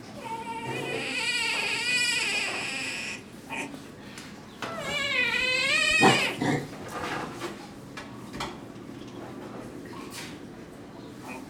Skull of a mountain paca (Cuniculus taczanowskii), illustrating its enlarged cheek bones (at red arrow), which house resonating chambers for its vocalizations.
cry,
cry.wav